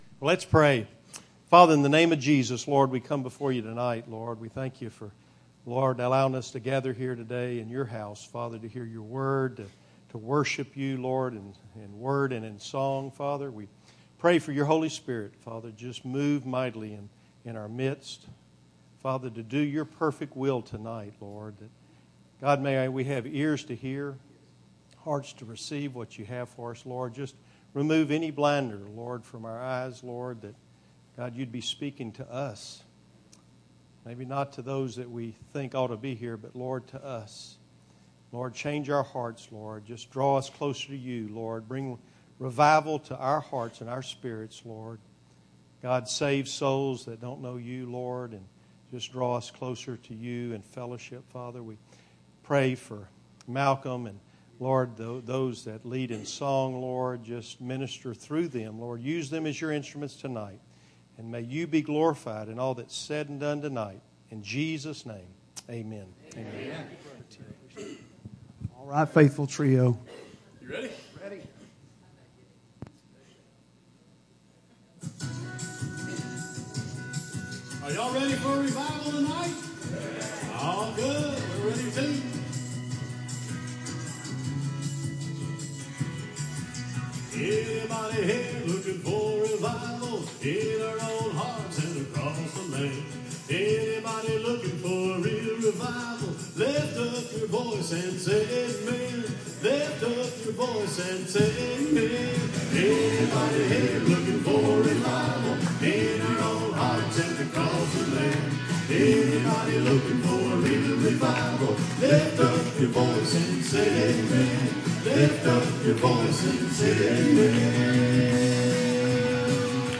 Bible Text: Matthew 7:24-27; Luke 6:46-49 | Preacher